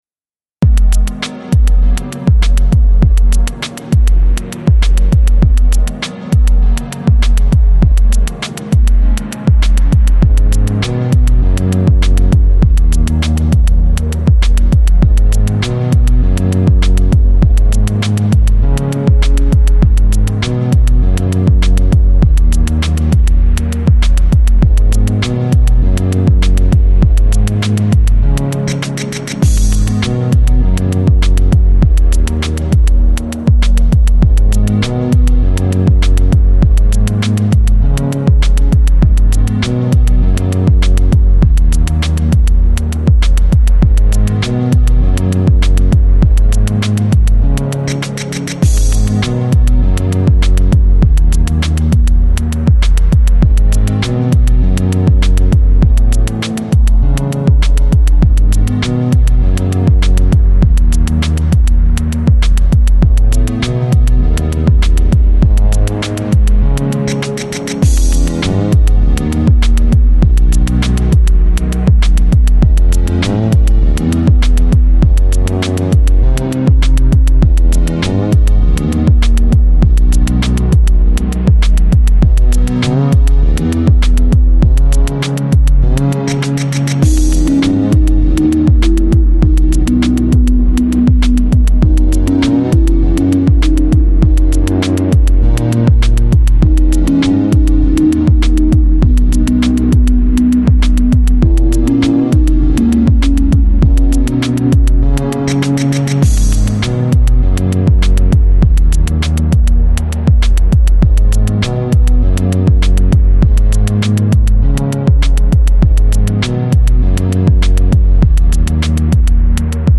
Electronic, Lounge, Chill Out, Downtempo, Ambient
Ambient & Chillout Moods